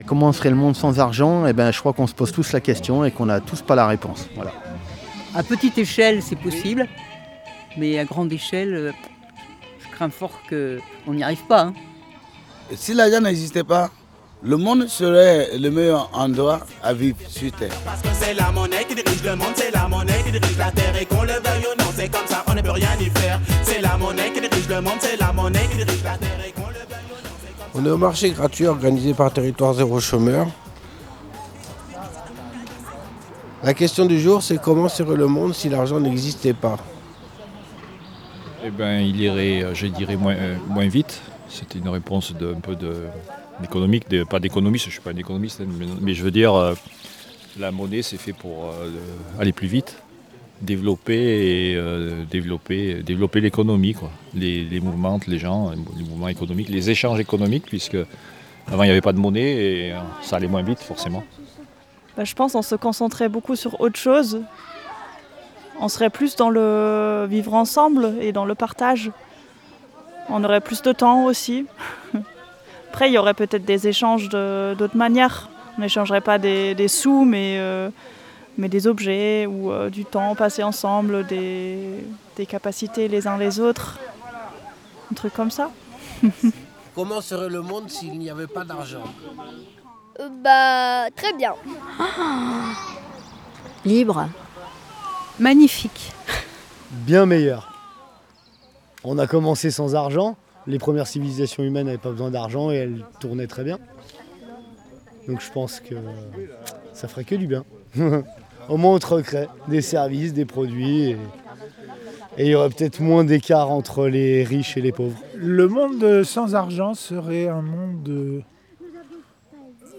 Micro-trottoir : comment serait le monde si l’argent n’existait pas ?
Réponses libres et gracieuses.
Micro-trottoir_Comment-serait-le-monde-si-largent-nexistait-pas_PAD.mp3